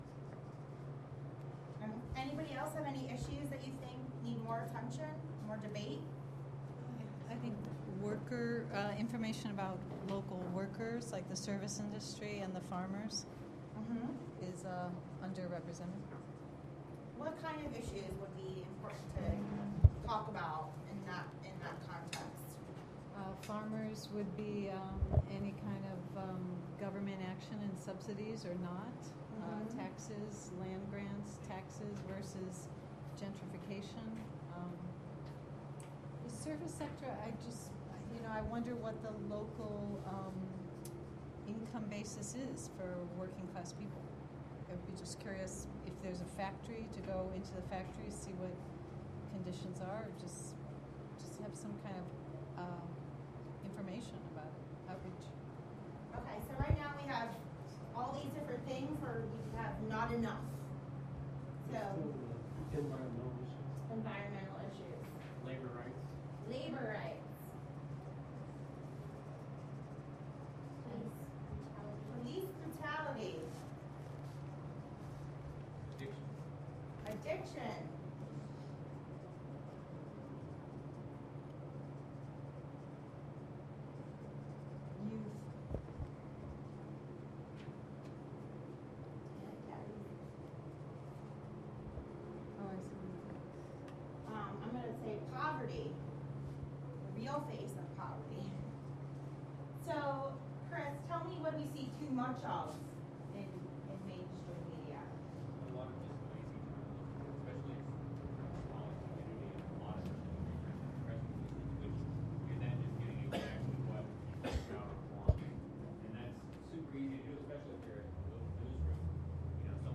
WGXC/Prometheus Radio Project Station Barnraising: Sep 24, 2010 - Sep 26, 2010